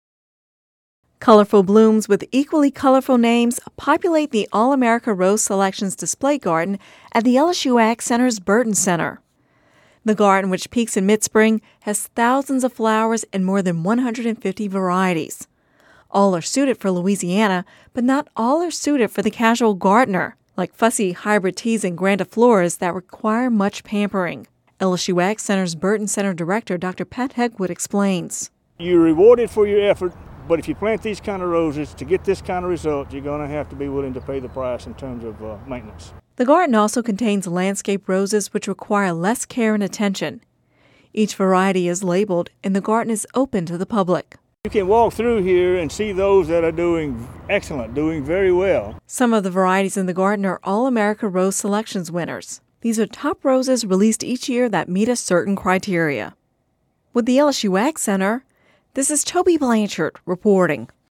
(Radio News 05/10/10) Colorful blooms with equally colorful names populate the All-America Rose Selections display garden at the LSU AgCenter’s Burden Center. The garden, which peaks about the middle of spring, has thousands of flowers and more than 150 varieties.